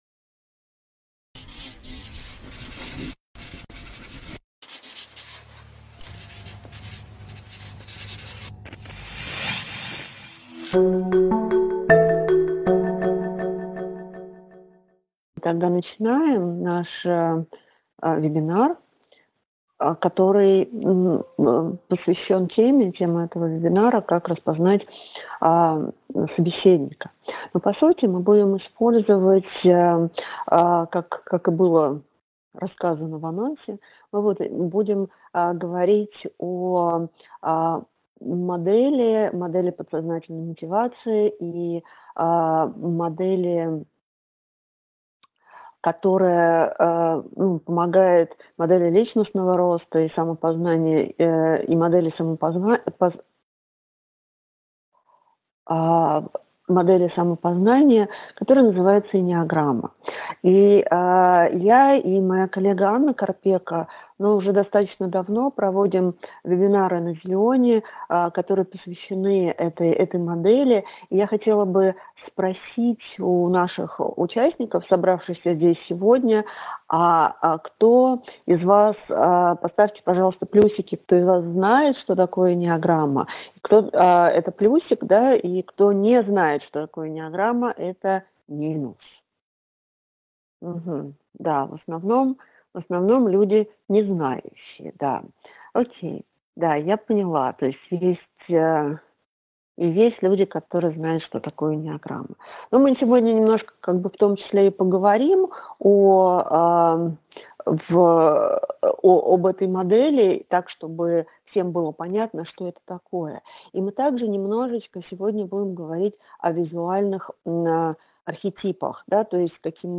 Аудиокнига Как распознать тип собеседника | Библиотека аудиокниг